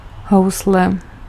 Ääntäminen
Synonyymit fiddle Ääntäminen US US : IPA : /ˌvaɪəˈlɪn/ IPA : [ˌvaɪ̯ɪ̈ˈlɪn] Lyhenteet ja supistumat (musiikki) Vl.